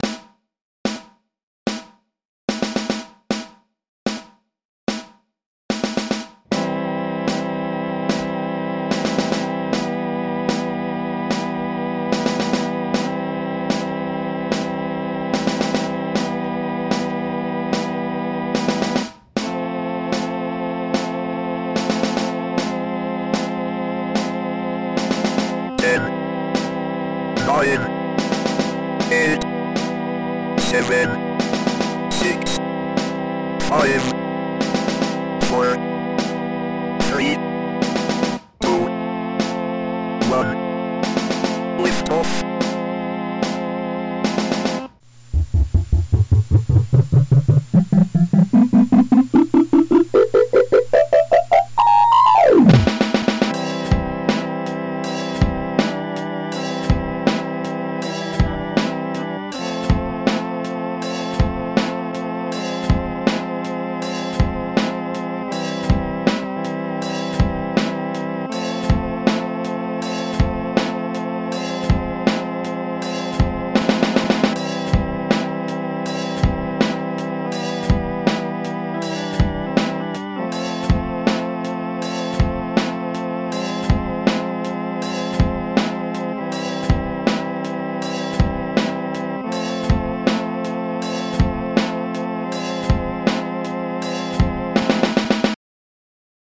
Instrumental Cover
Instrumental cover